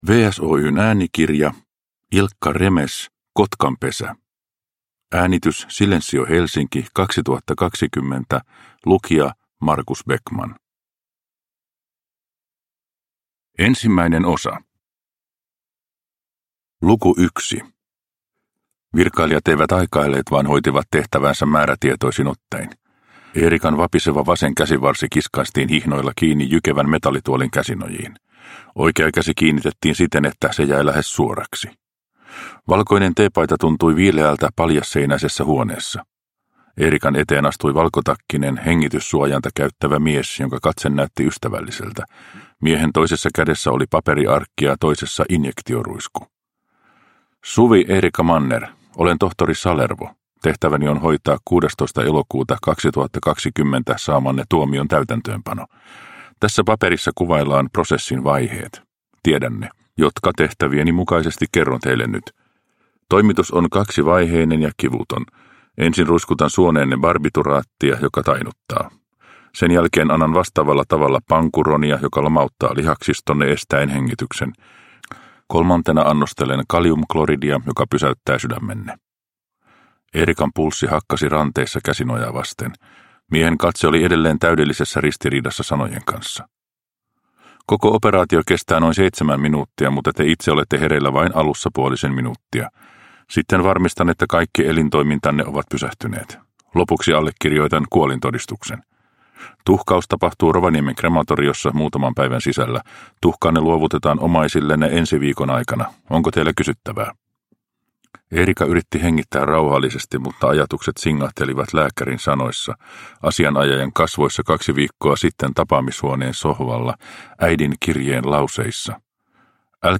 Kotkanpesä – Ljudbok – Laddas ner